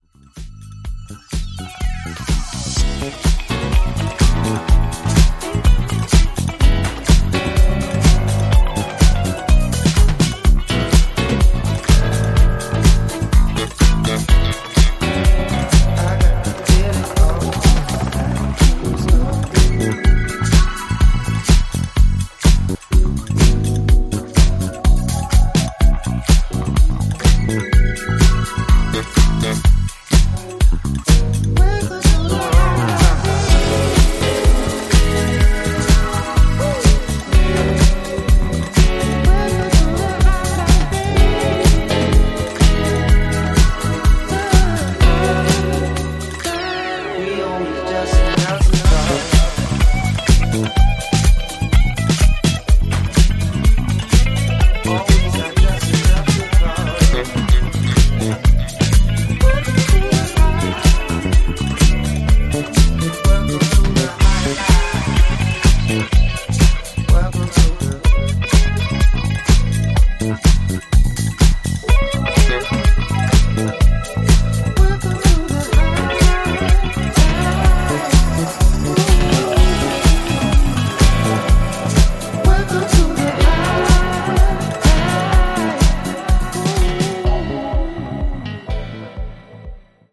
a soulful evolution of a years-long work